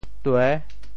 潮州 duê6